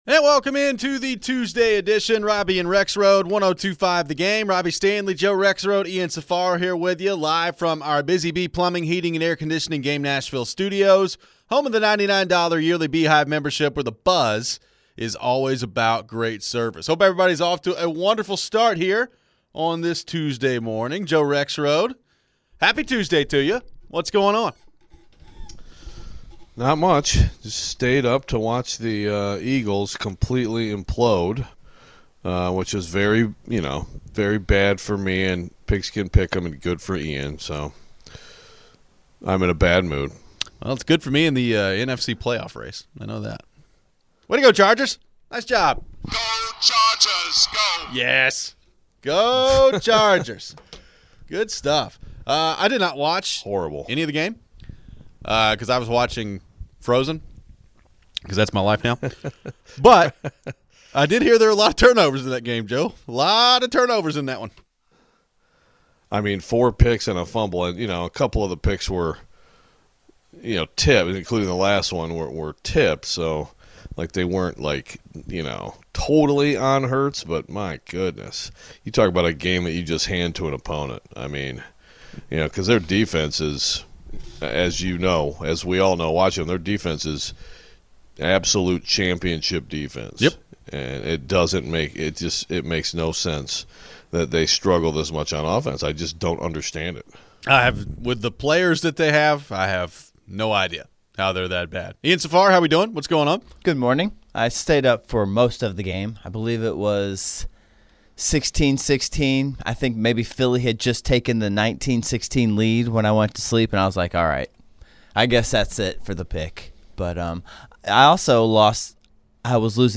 We take your phones and discuss more on the AFC playoff picture.